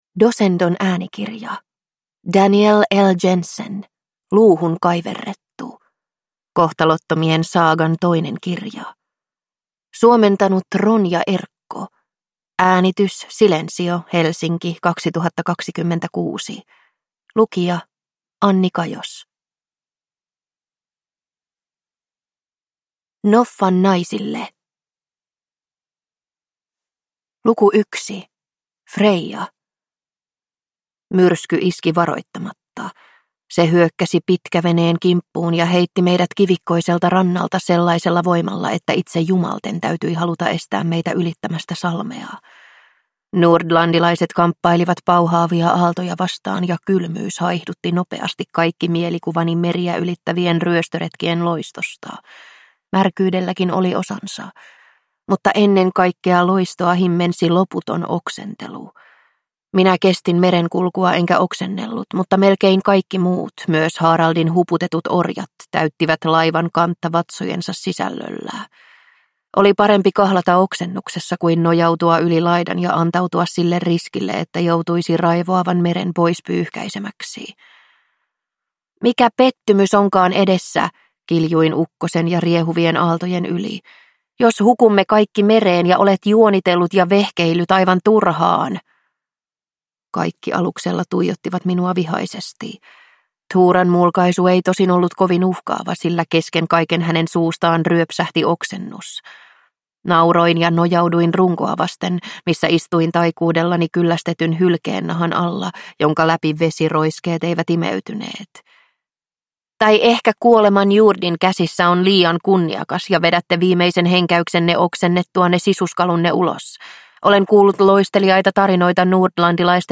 Luuhun kaiverrettu – Ljudbok